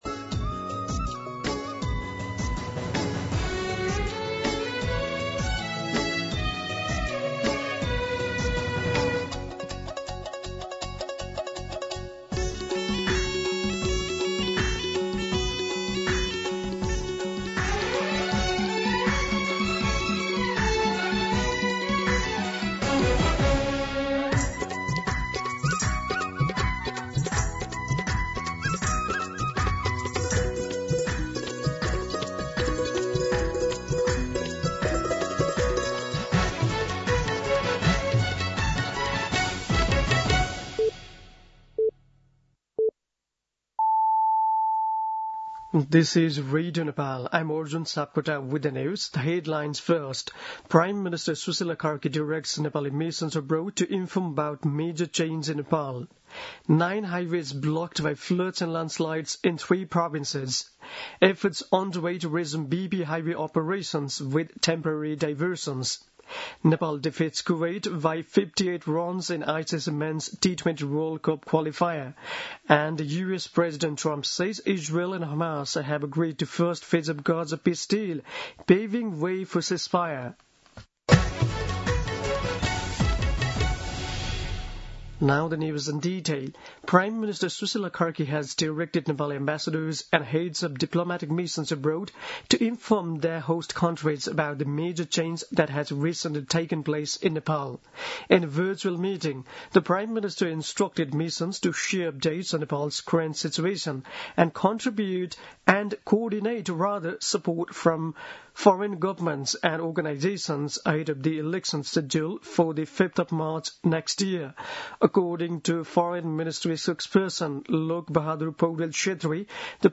दिउँसो २ बजेको अङ्ग्रेजी समाचार : २३ असोज , २०८२